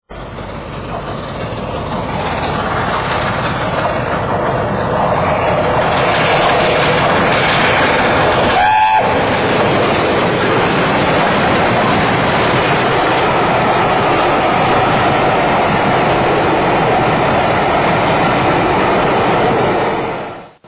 Sounds of L. N. E. R. steam locomotives